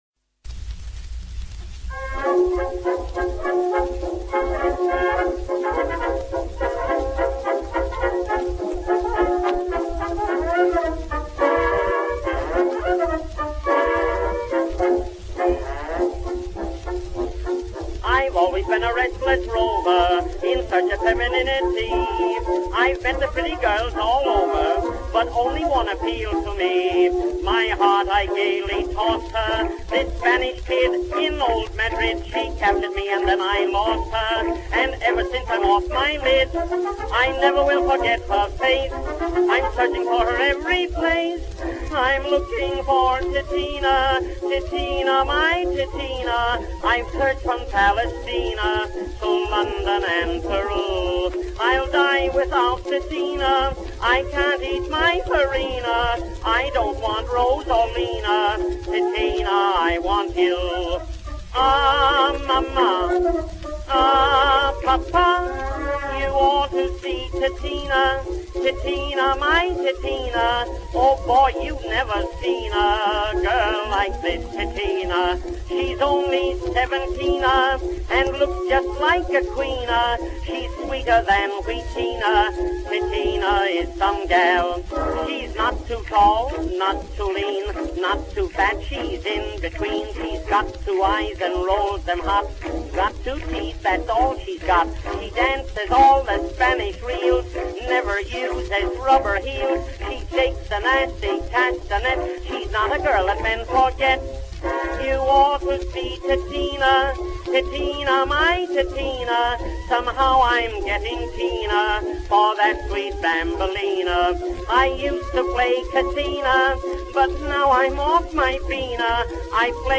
очень знаменитого в Америке начала XX века певца-тенора